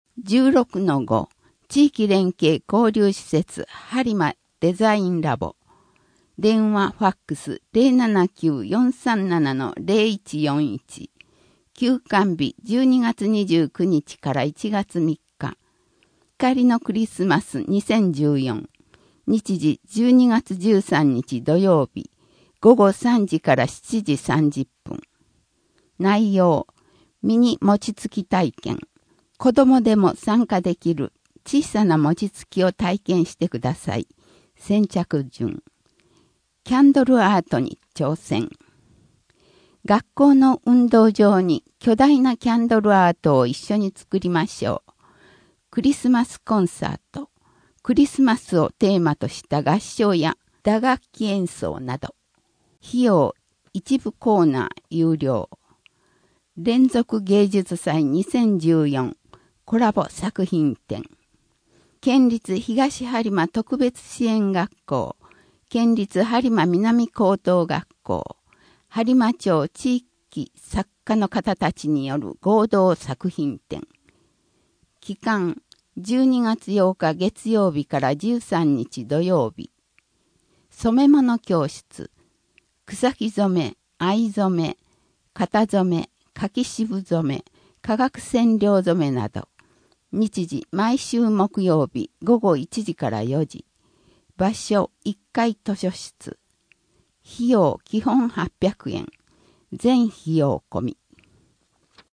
声の「広報はりま」12月号
声の「広報はりま」はボランティアグループ「のぎく」のご協力により作成されています。